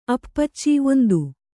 ♪ appacci